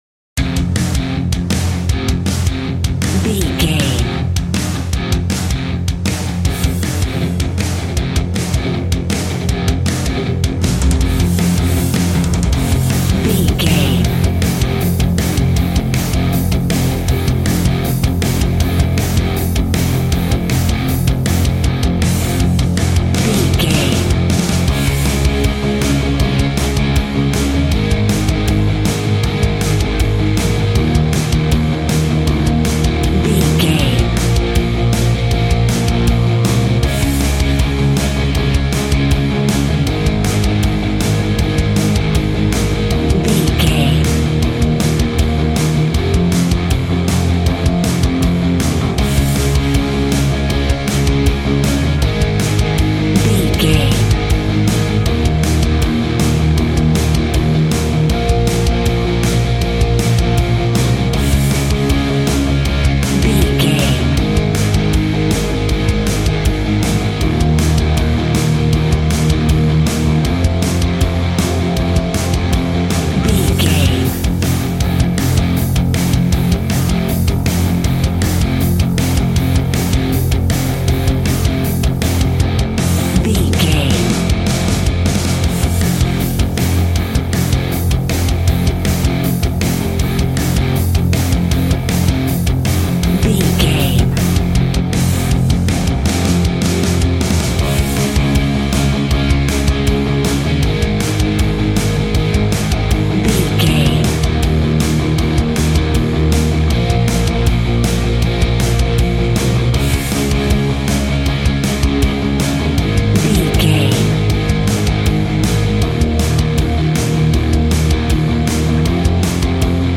Aeolian/Minor
angry
aggressive
electric guitar
drums
bass guitar